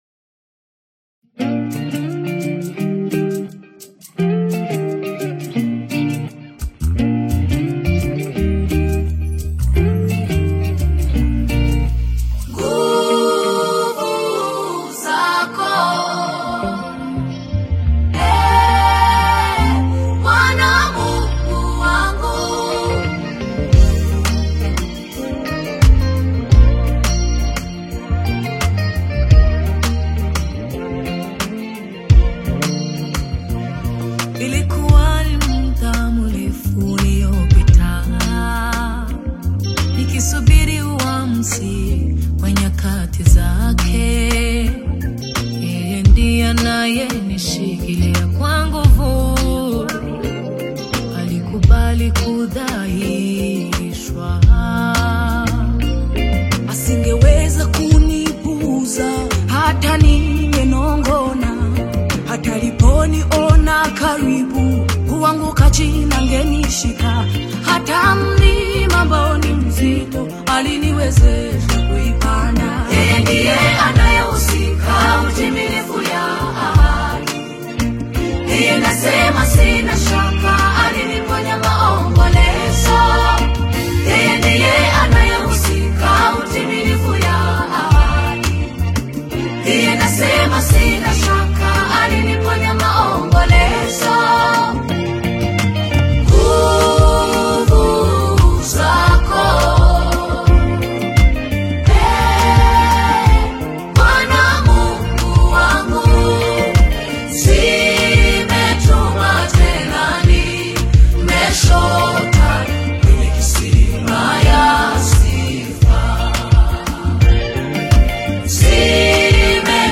AudioGospel
gospel single